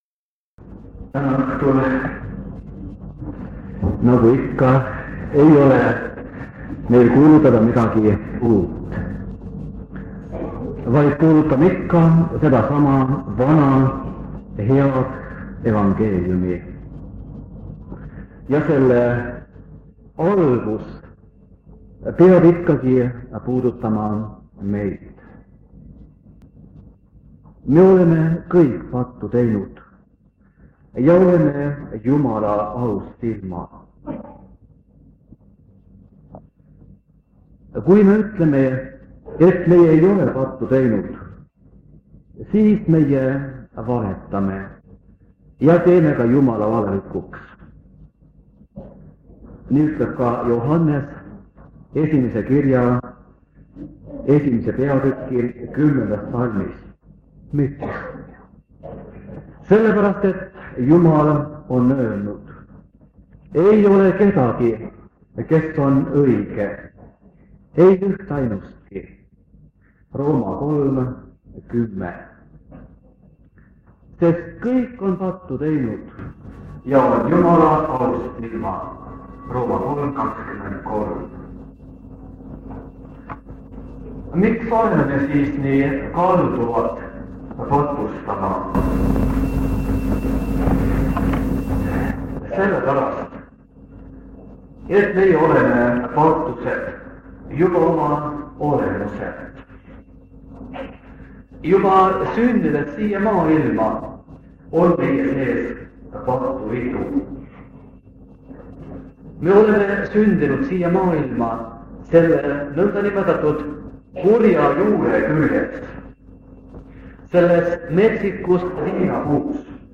Jutluste miniseeria vanadelt lintmaki lintidelt 1974 aasta sügisel.